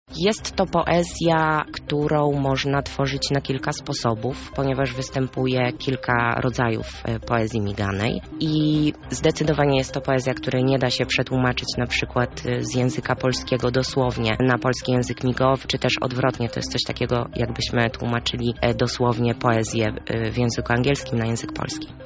Poranna Rozmowa